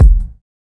Wu-RZA-Kick 5.wav